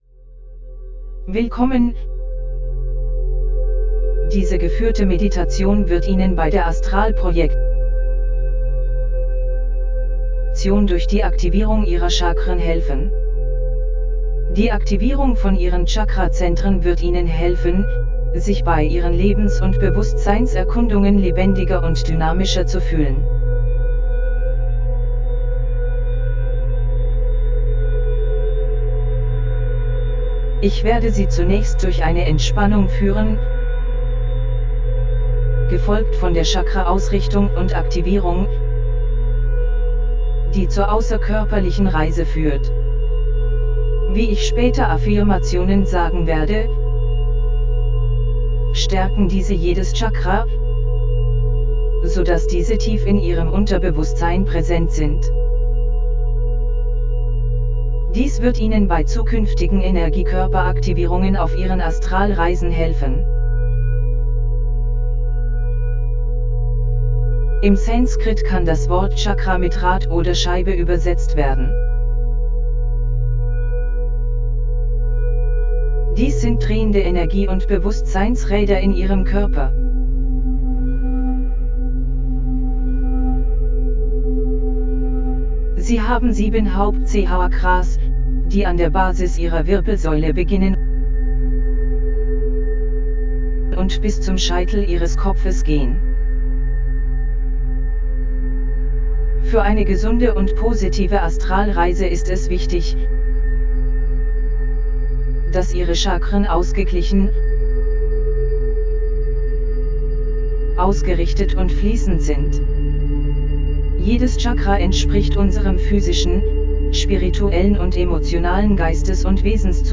Willkommen zu dieser geführten Astralprojektions-Meditation, bei der Sie durch die Chakra-Aktivierungstechnik geführt werden.
Bei dieser Technik geht es darum, sieben Ihrer Hauptchakren zu aktivieren und ein Astraldoppel oder Vehikel zu erschaffen und dann Ihr Bewusstsein auf dieses Vehikel zu übertragen. Für das beste Erlebnis empfehlen wir die Verwendung von Kopfhörern / Ohrstöpseln, da die Meditation in binaurale 3-Hz-Delta-Beats eingebettet ist.
OBEGuidedAstralProjectionChakraMeditationHypnosisDE.mp3